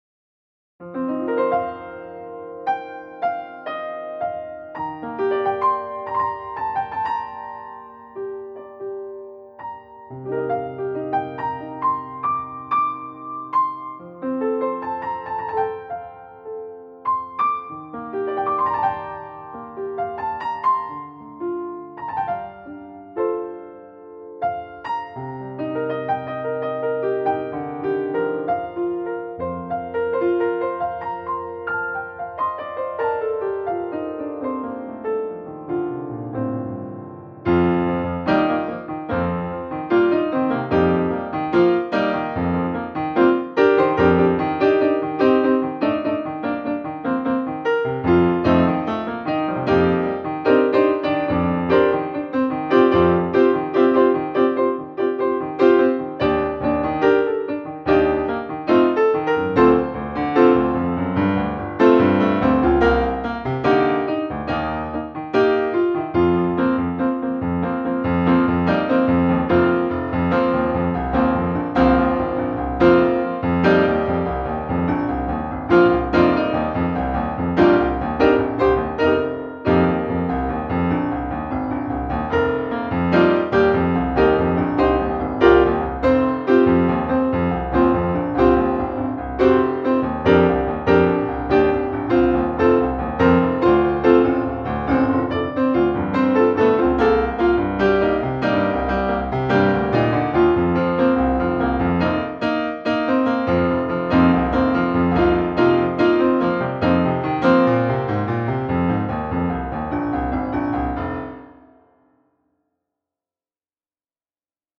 Lied für den 31.05.2020